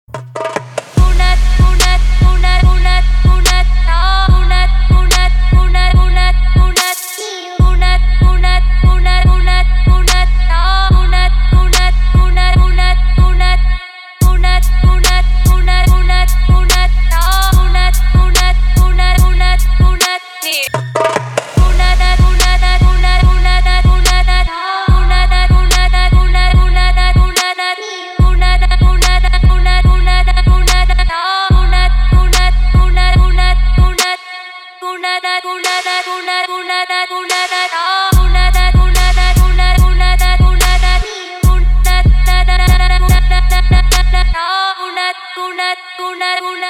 good_trap_music___arabik__1.mp3